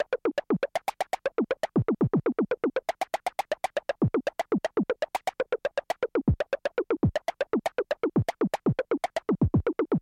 标签： MIDI-速度-21 F4 MIDI音符-66 罗兰-SH-2 合成器 单票据 多重采样
声道立体声